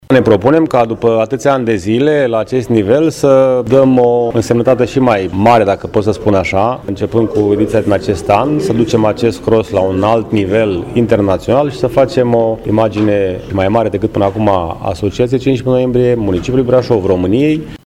Primarul Brașovului, George Scripcaru: